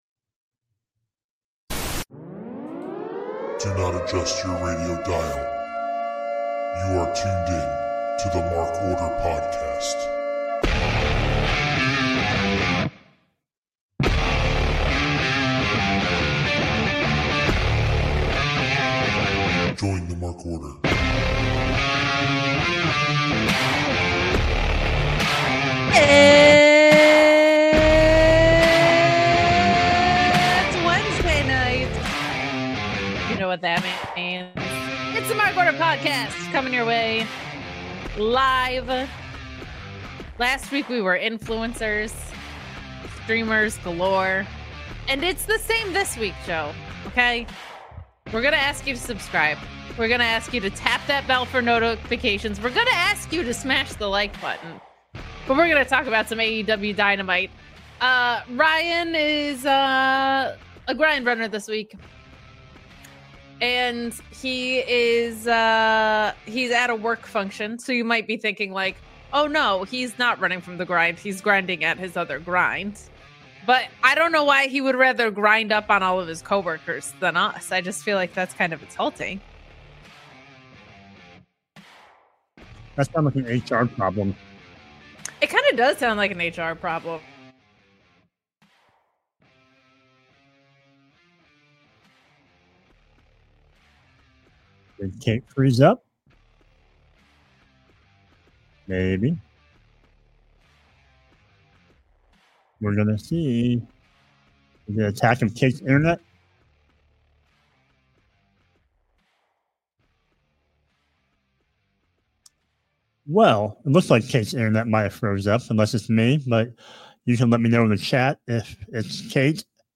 This dynamic duo discusses the go-home episode of Dynamite leading into Dynasty this Sunday.